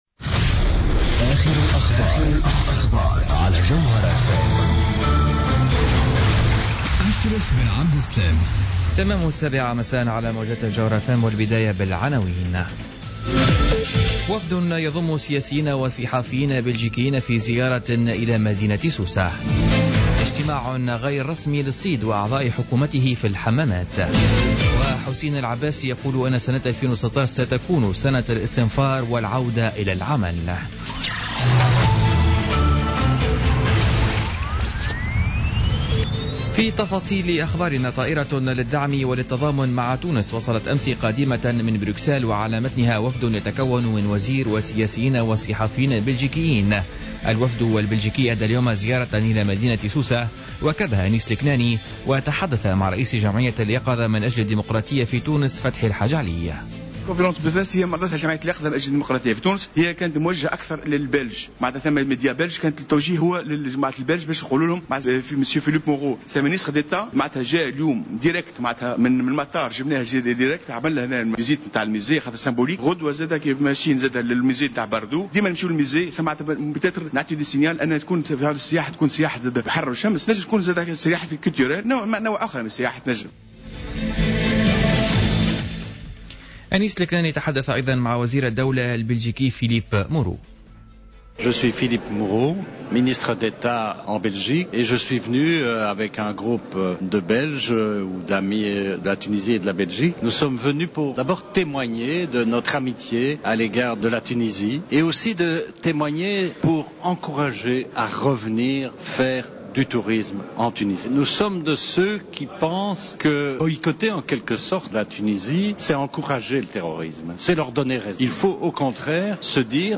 نشرة أخبار السابعة مساء ليوم السبت 19 سبتمبر 2015